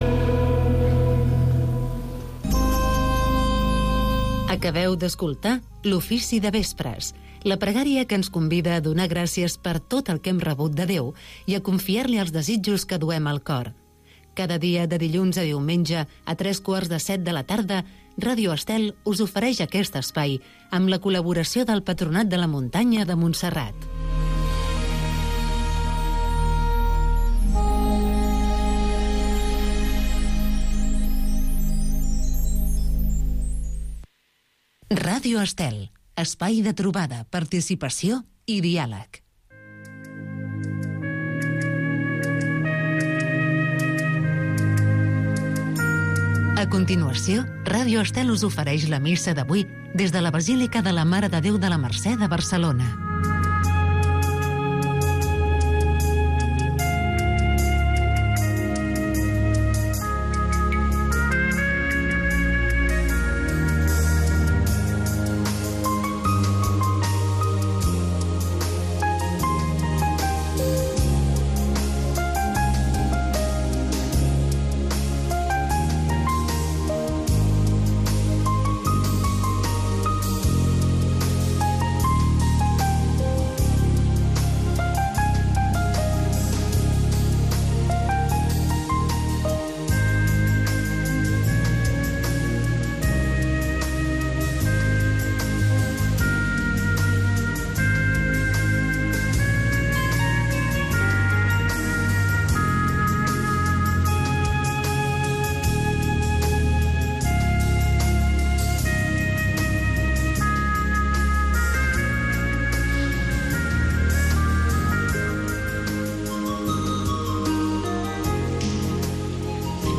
Cada dia pots seguir la Missa en directe amb Ràdio Estel.